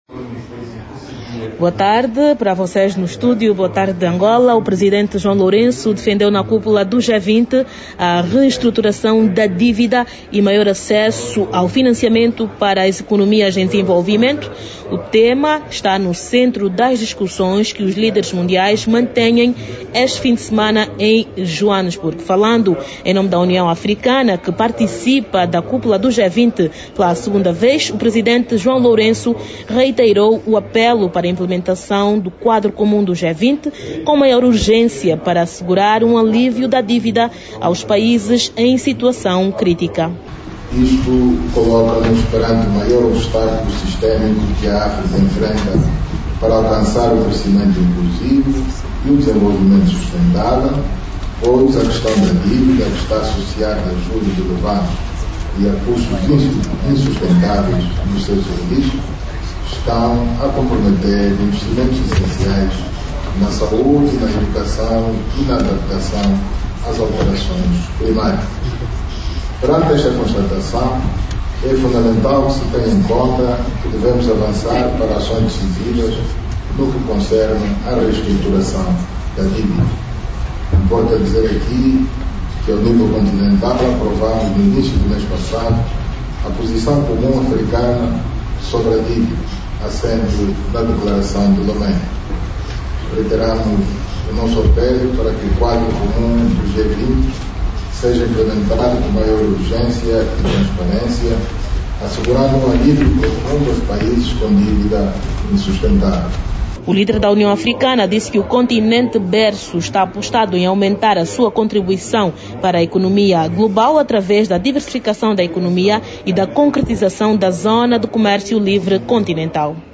a partir de Joanesburgo